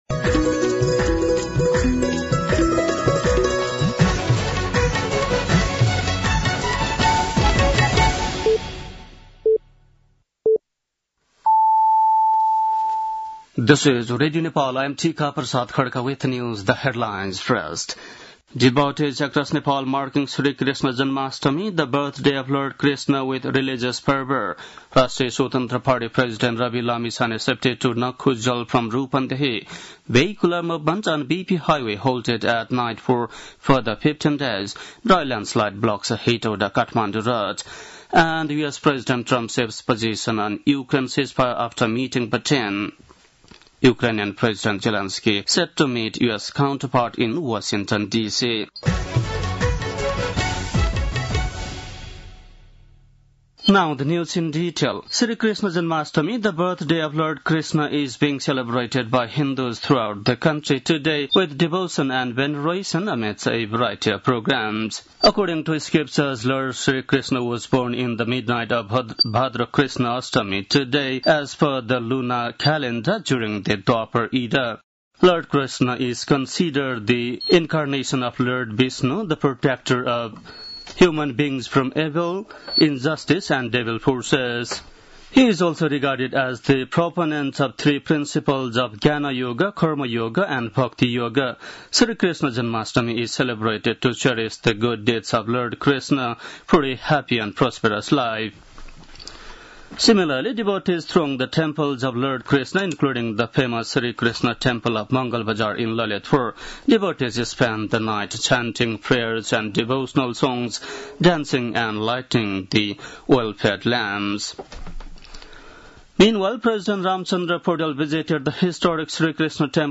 बेलुकी ८ बजेको अङ्ग्रेजी समाचार : ३१ साउन , २०८२
8-PM-English-NEWS-04-31.mp3